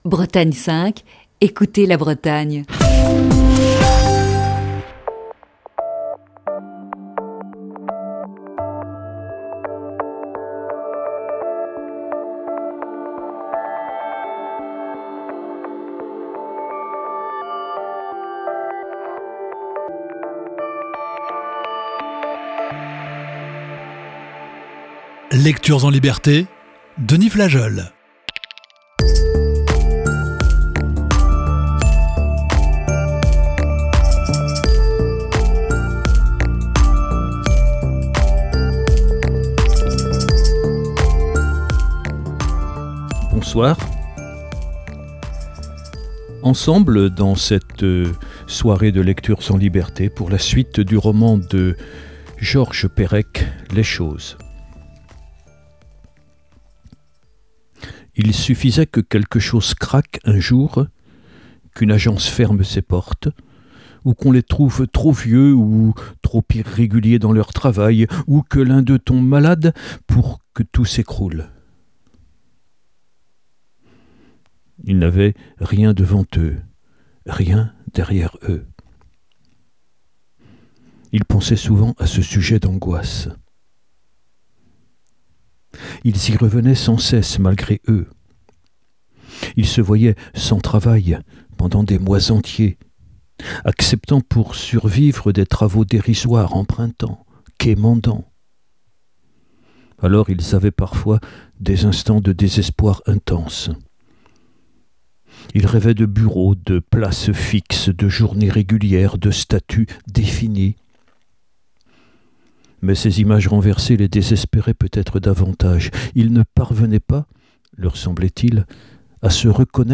Émission du 18 juin 2021.